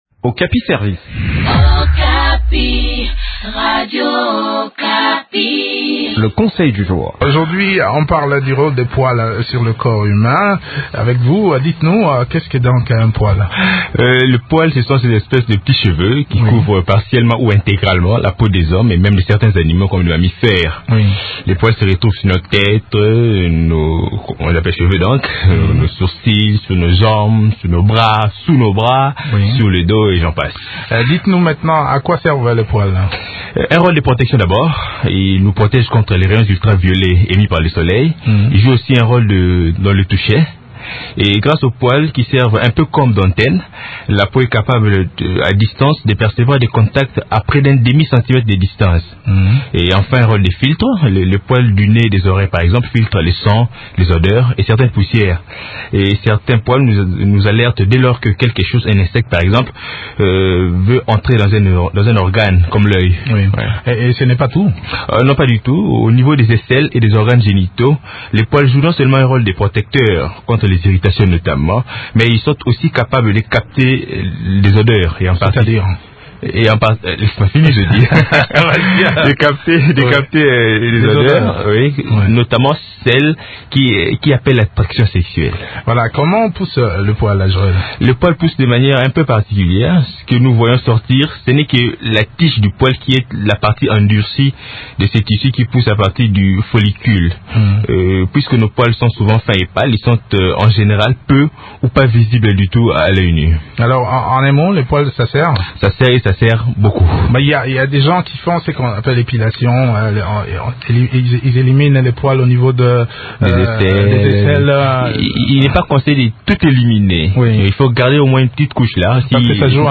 Eléments de réponse dans cette enquête